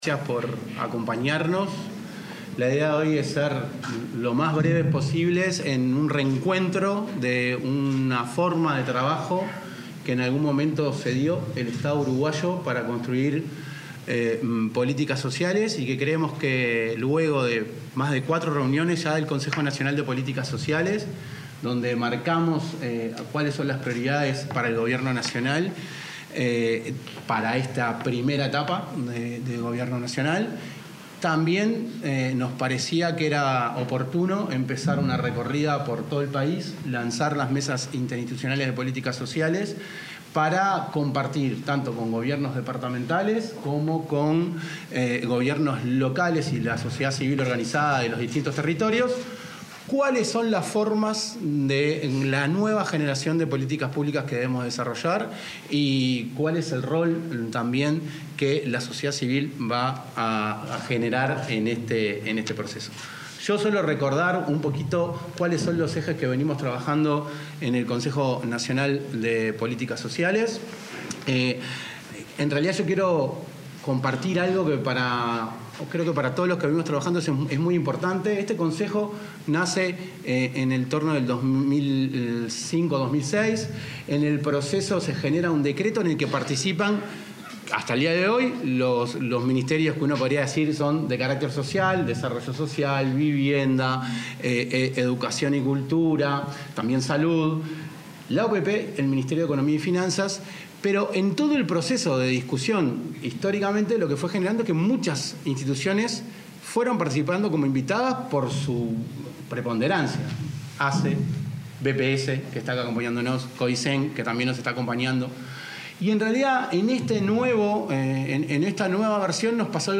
Palabras de autoridades del Ministerio de Desarrollo Social
Con motivo del relanzamiento de las Mesas Interinstitucionales de Políticas Sociales, se expresaron el ministro de Desarrollo Social, Gonzalo Civila,